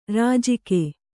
♪ rājike